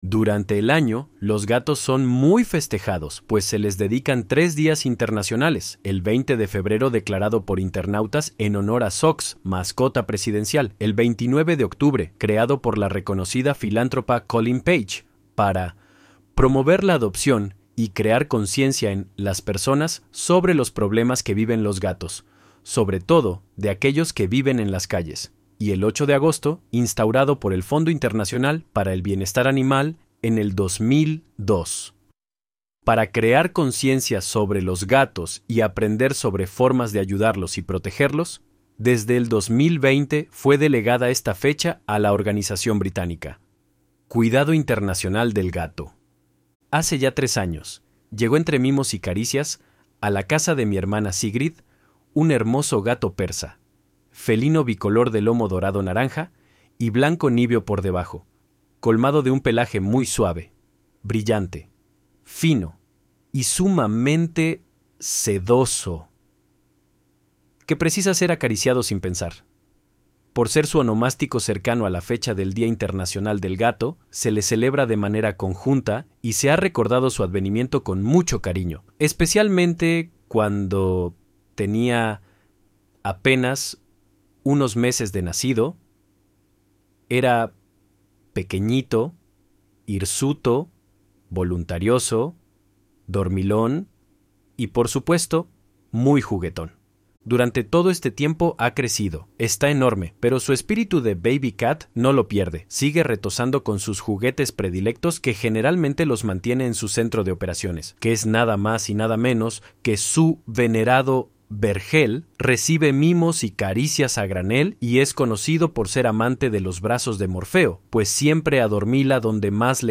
ElevenLabs_Untitled_Project_f34bf3.mp3